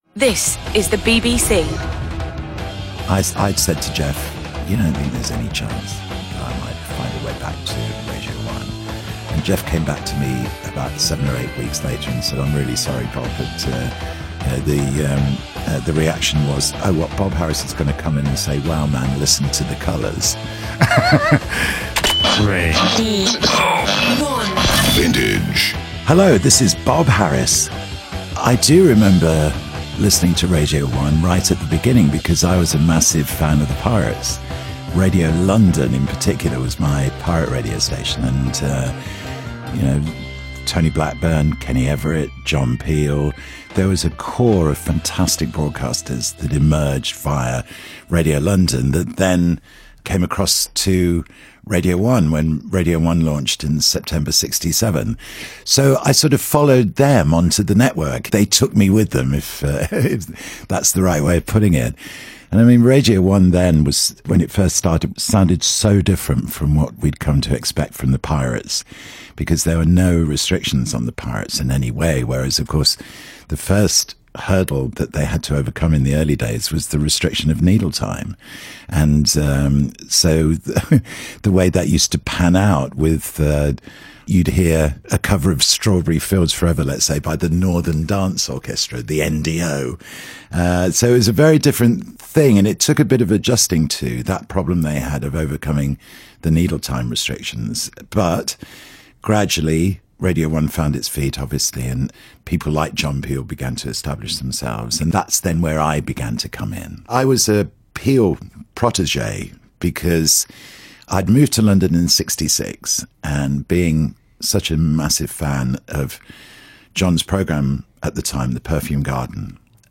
Bob Harris radio one vintage interview podcast